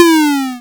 lose2.wav